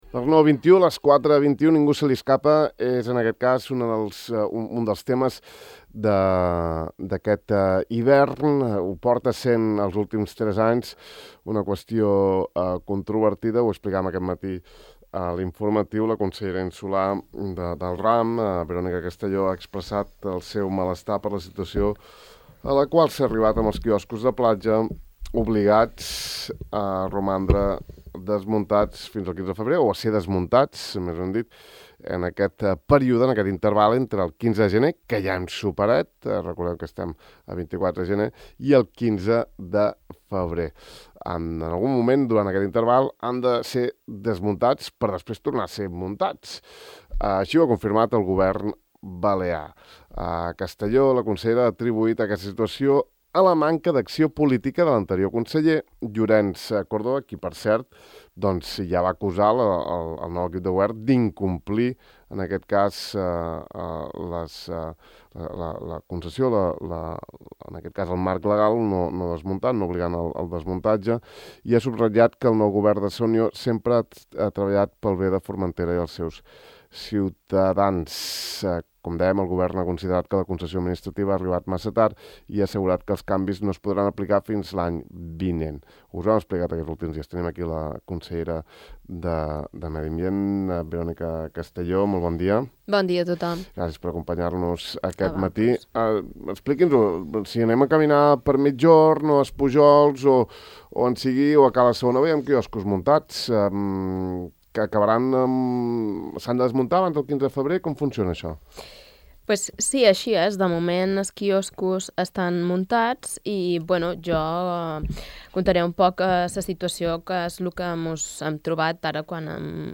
La consellera insular de Medi Ambient, Verónica Castelló, ha explicat a Ràdio Illa que el Consell estarà obligat a portar a terme inspeccions per comprovar que els quioscos de platja estiguin desmuntats entre el 15 de gener al 15 de febrer, un deure que, de moment, cap dels vuit concessionaris ha complert.